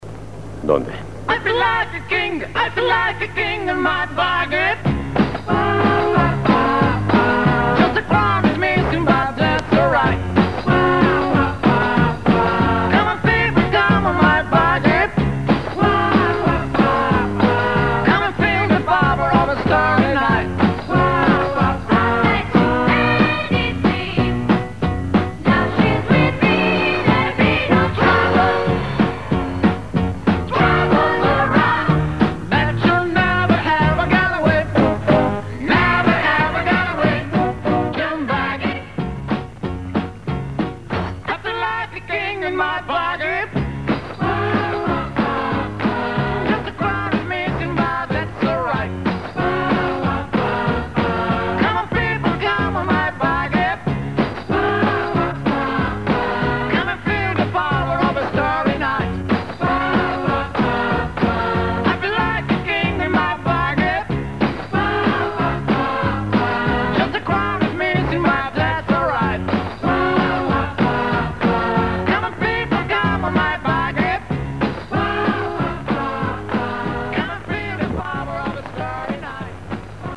tema musical